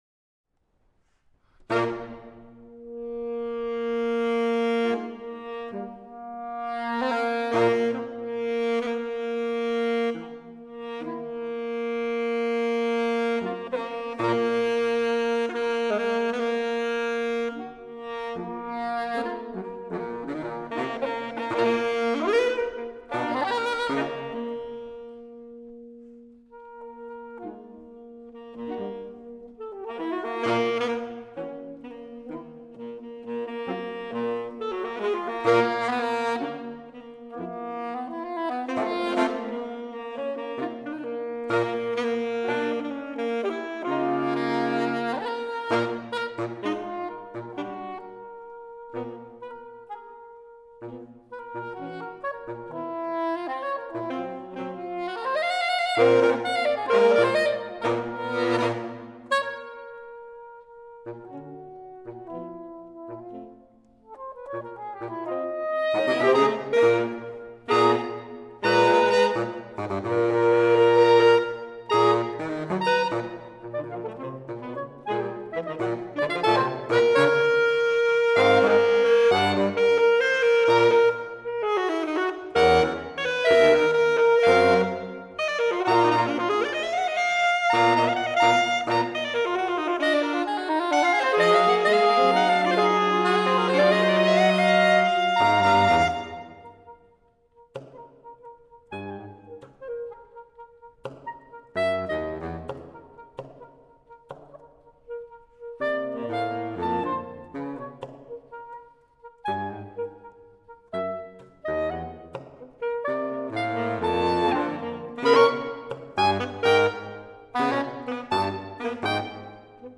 2001 live recording